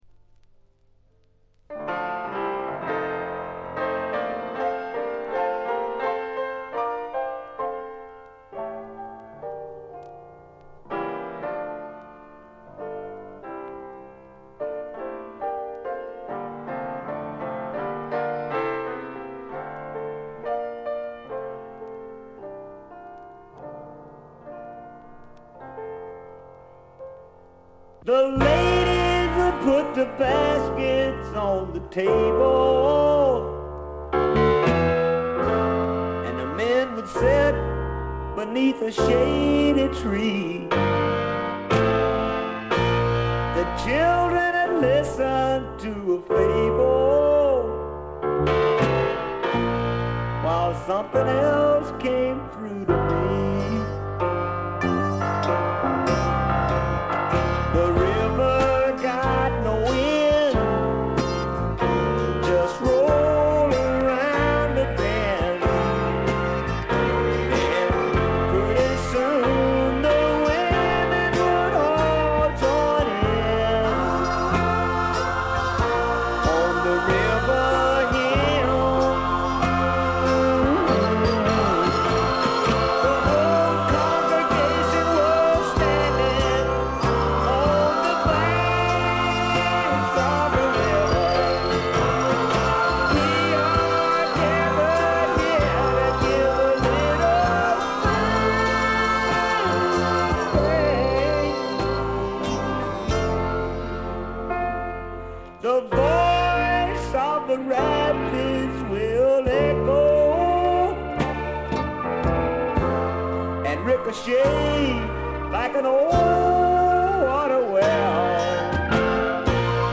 部分試聴ですが、軽微なチリプチ程度。
試聴曲は現品からの取り込み音源です。